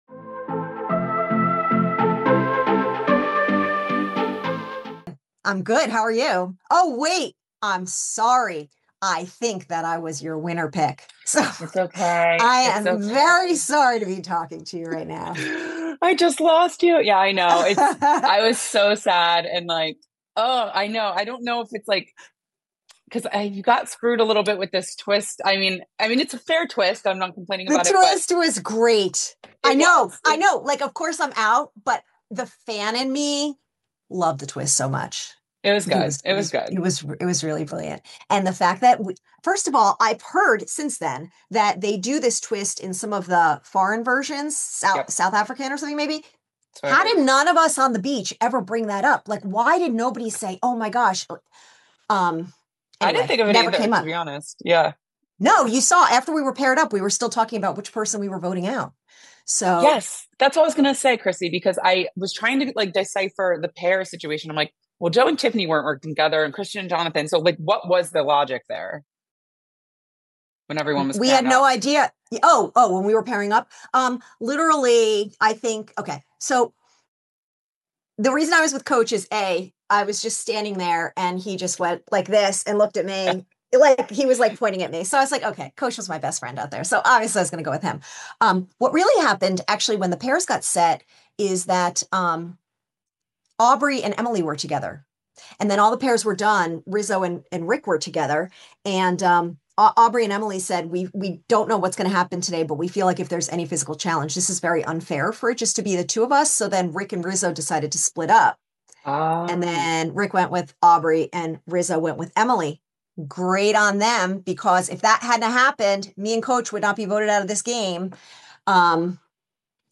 Survivor 50 Exit Interview: 12th Player Voted Out